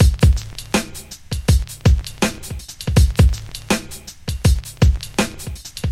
Boing
描述：通过弹动相机三脚架快装板底部的紧固键而产生。
标签： 弹簧
声道立体声